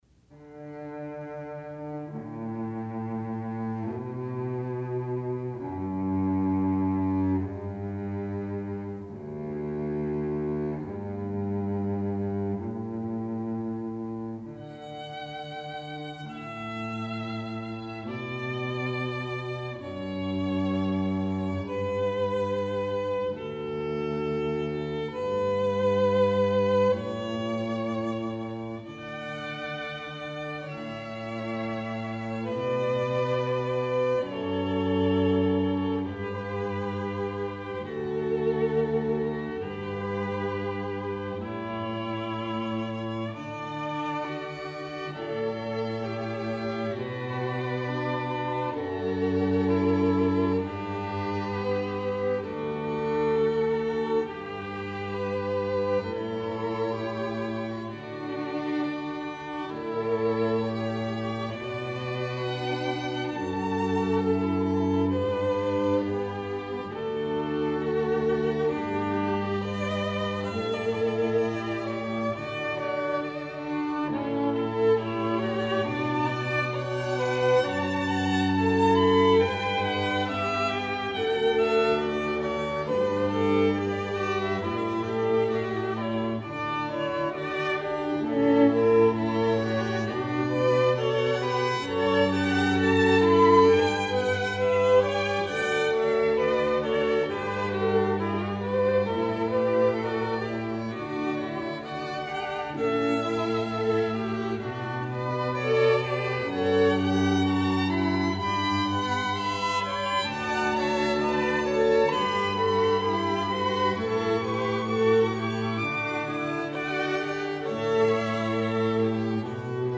Strings
Each player of this string quartet brings a diversity of experience, from studies with world renown teachers, to performances with great orchestras.